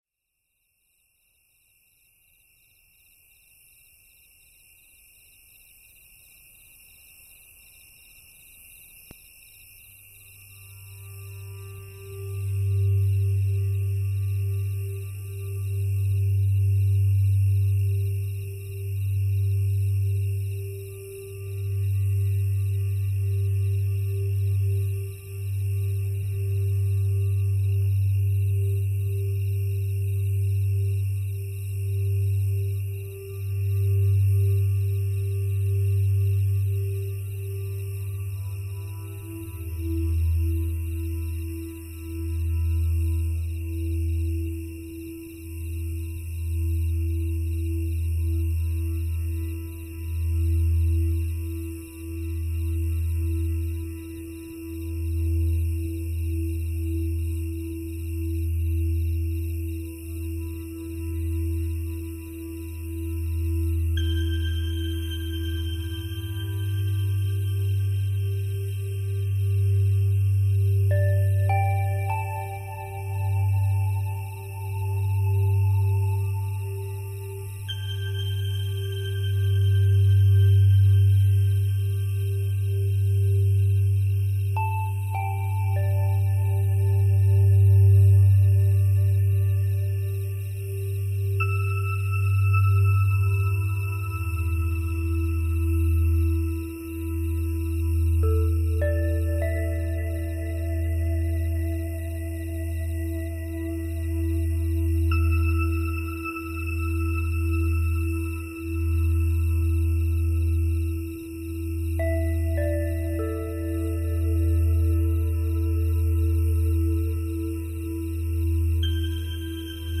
nature sounds and atmospheric sounds, perfect for relaxation
new age
ambient